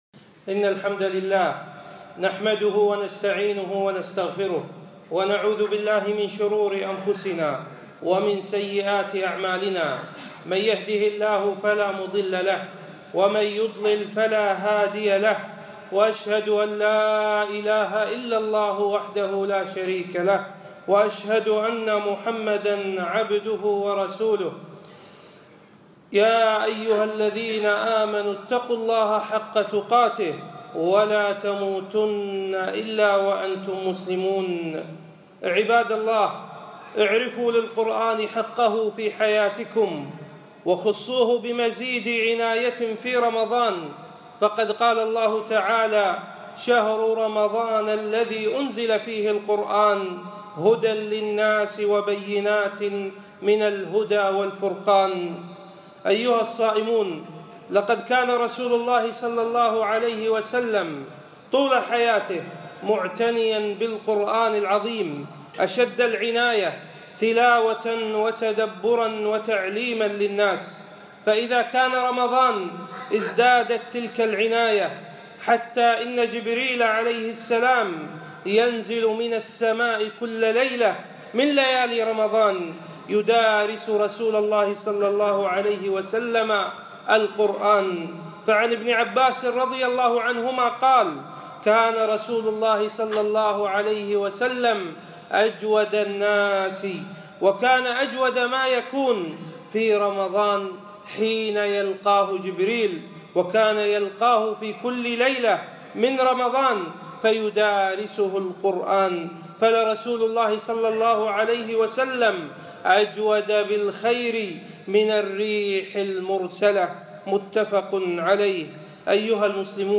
خطبة - القرآن في رمضان